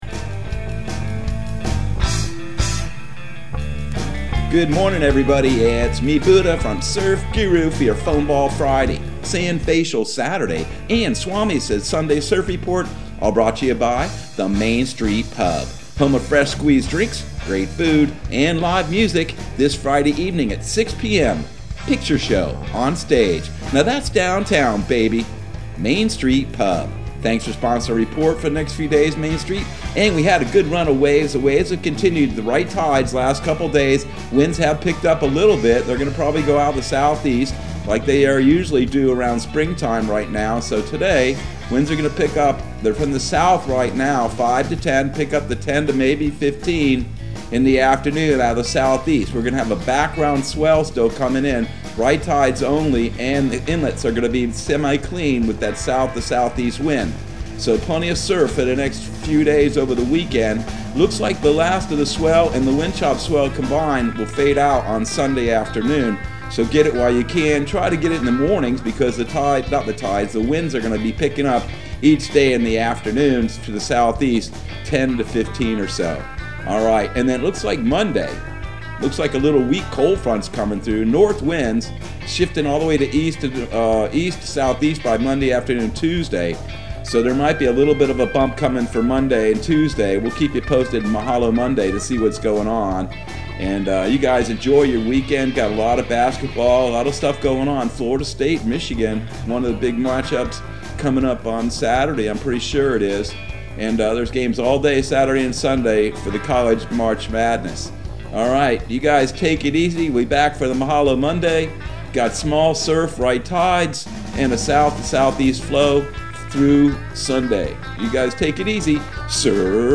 Surf Guru Surf Report and Forecast 03/26/2021 Audio surf report and surf forecast on March 26 for Central Florida and the Southeast.